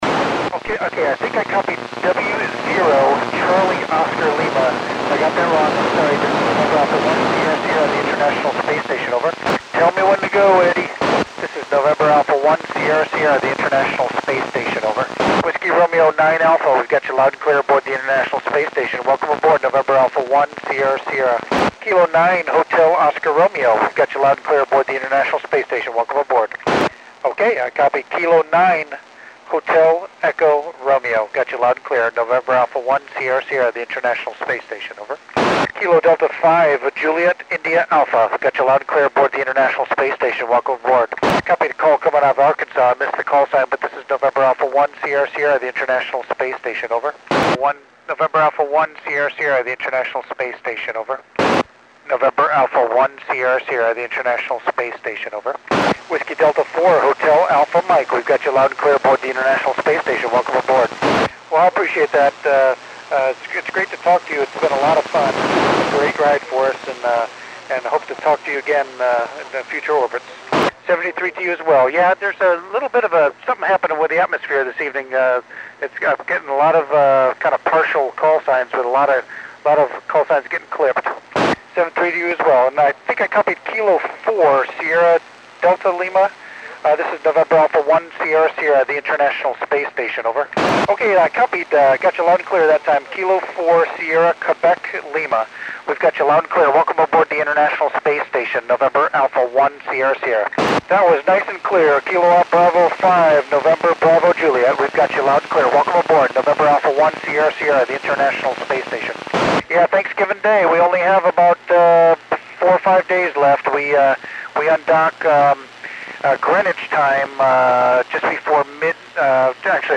This was only an 11 degree elevation pass, so quality is poor. Col. Wheelock mentions recording a U.S. pass later tonight or tomorrow night in high-def for subsequent viewing on NASA TV.